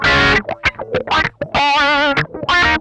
MANIC WAH 8R.wav